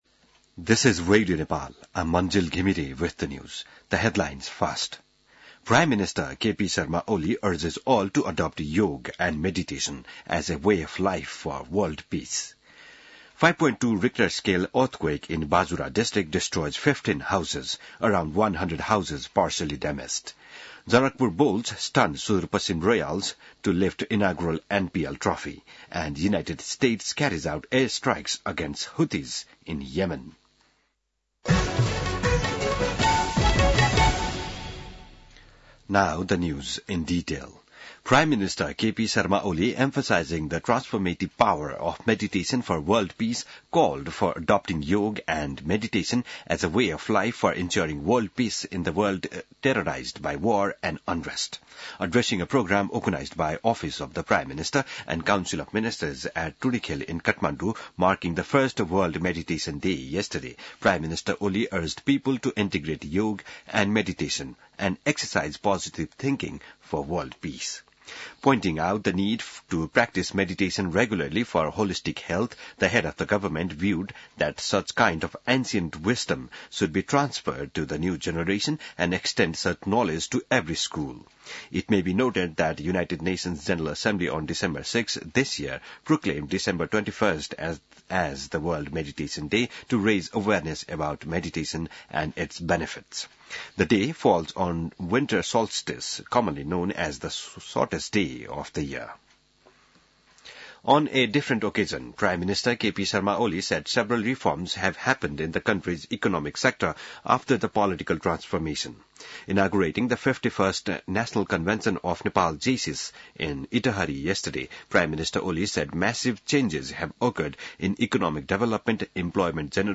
बिहान ८ बजेको अङ्ग्रेजी समाचार : ८ पुष , २०८१